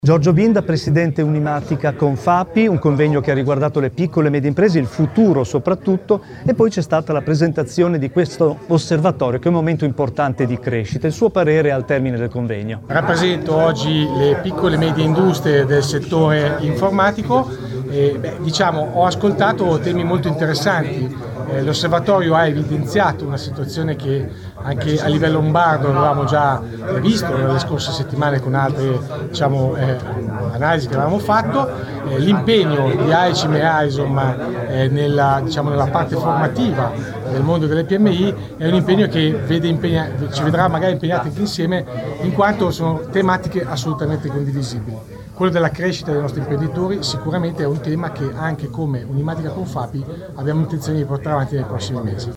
Lieti di essere main sponsor di questo importante evento, “Strategia per il rinascimento delle PMI”, organizzato AICIM e AISOM presso il Grand Hotel Magestic già Baglioni di Bologna.
Intervista a